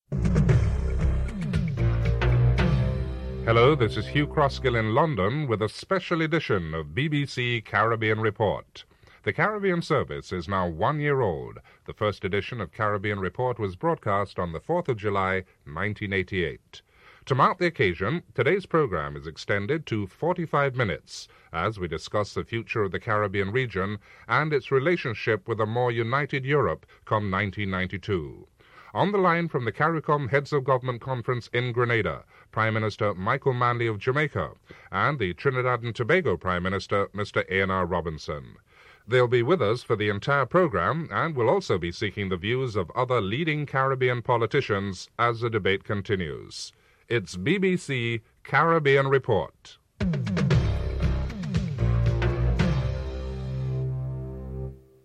A Special edition of the BBC Caribbean Report on its first anniversary, 4th July 1988. To mark the occasion, a discussion on the future of the Caribbean region and and its relationship with Europe come 1992.